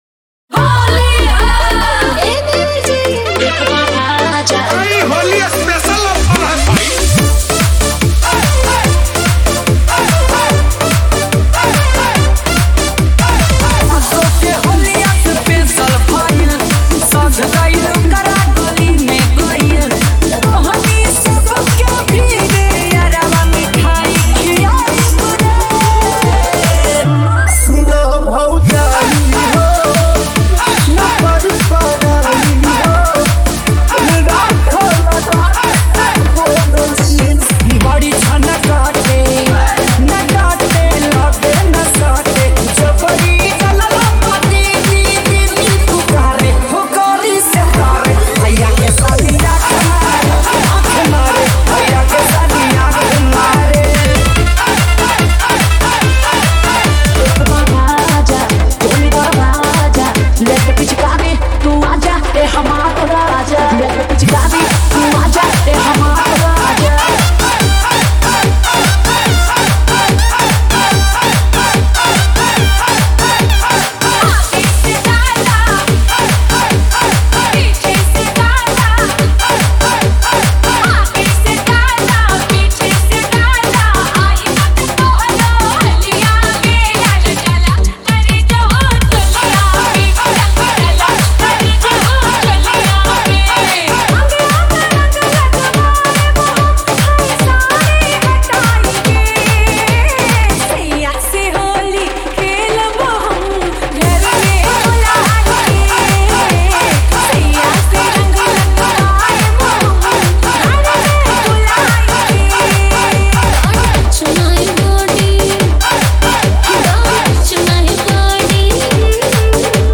Album : Single DJ Remix